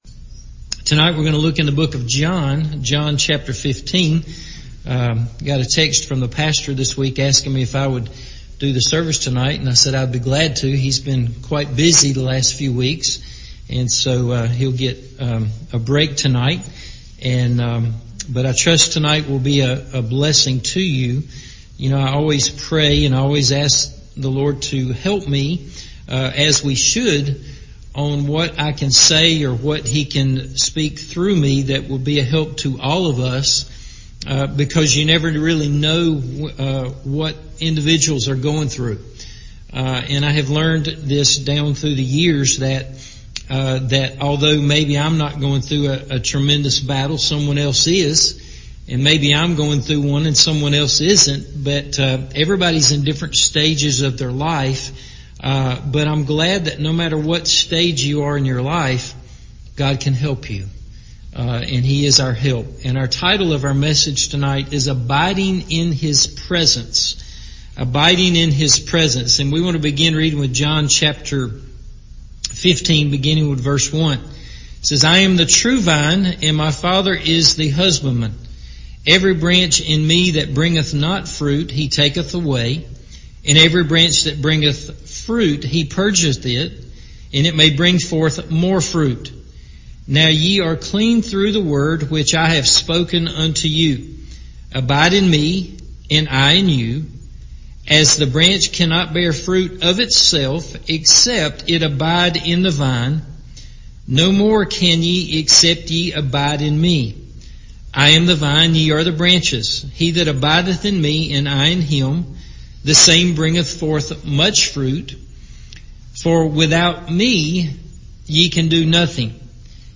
Abiding in His Presence – Evening Service